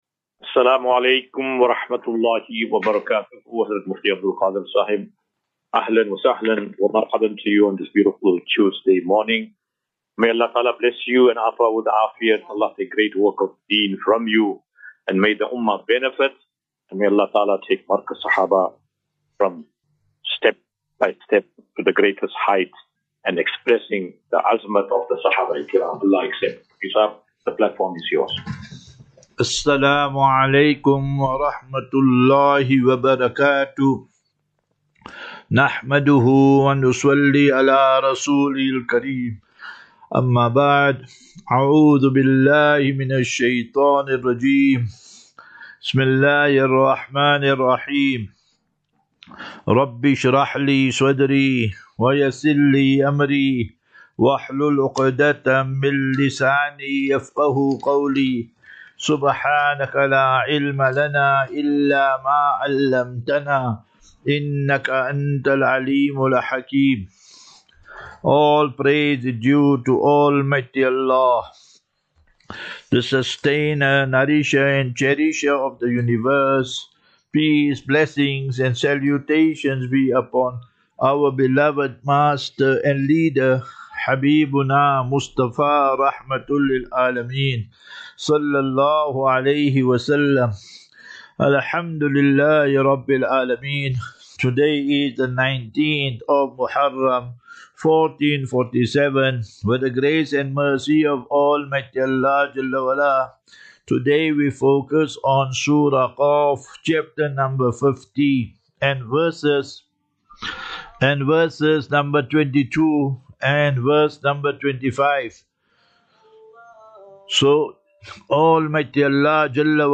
Assafinatu - Illal - Jannah. QnA